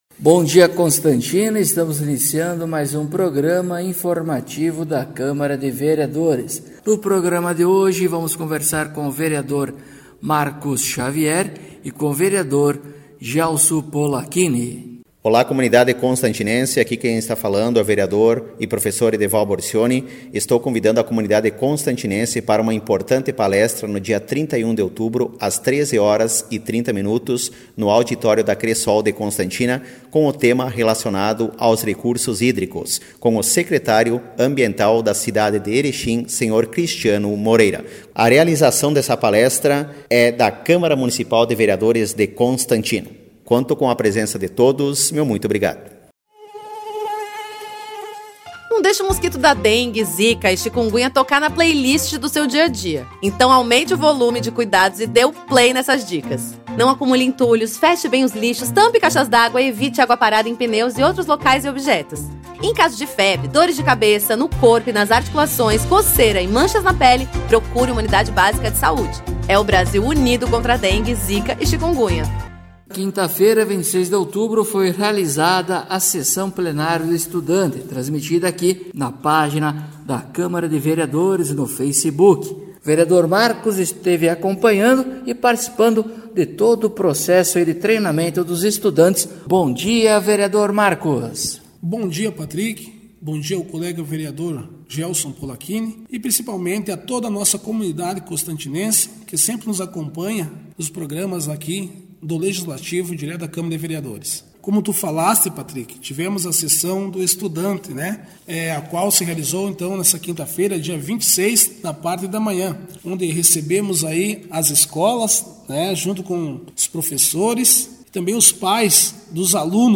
Acompanhe o programa informativo da câmara de vereadores de Constantina com o Vereador Marcos Xavier e o Vereador Gelso Polaquini.